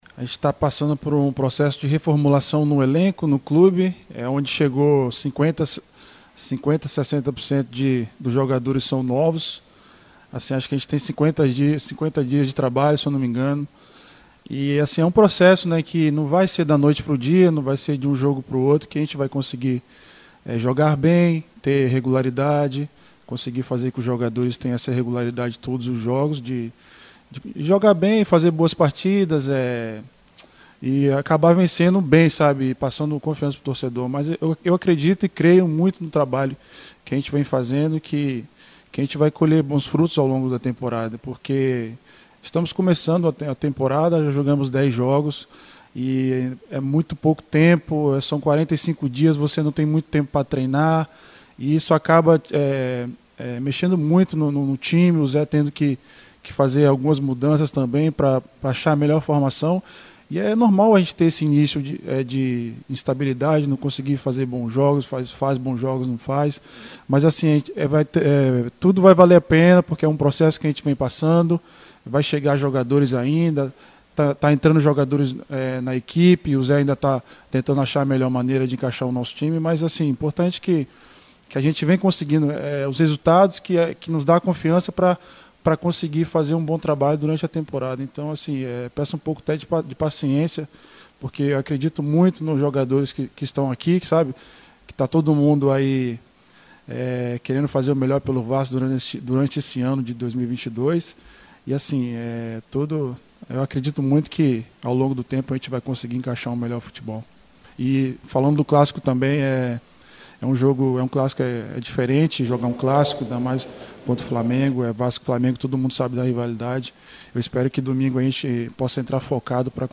O zagueiro Anderson Conceição, um dos líderes da equipe, sai em defesa do grupo e pede paciência aos torcedores neste momento de remontagem vascaína.